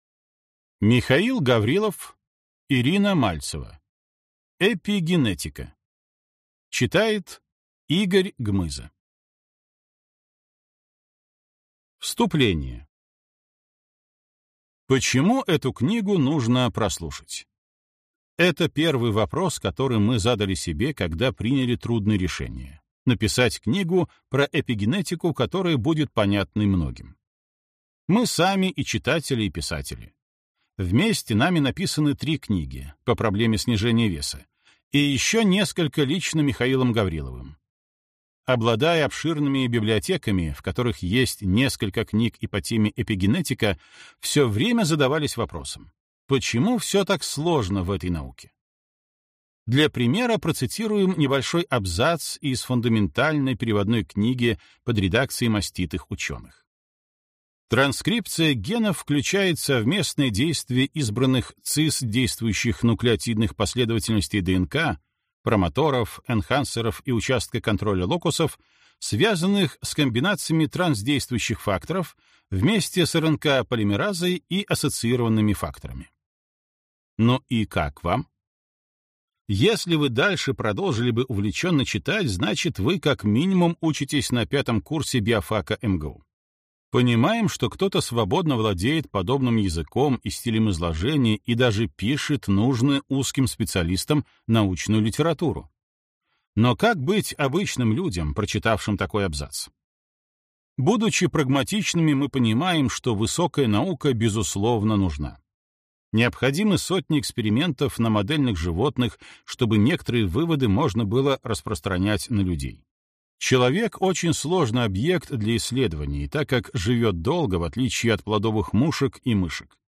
Аудиокнига Эпигенетика. Управляй своими генами | Библиотека аудиокниг
Прослушать и бесплатно скачать фрагмент аудиокниги